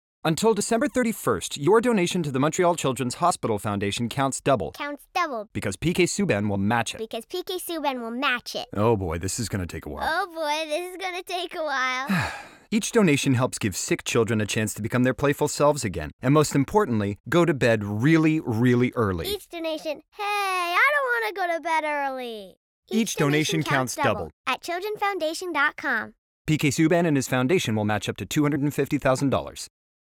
Publicité (Montreal Children's Foundation) - ANG